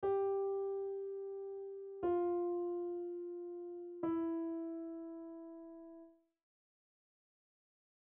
Piano Notes
gfe.mp3